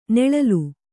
♪ neḷalu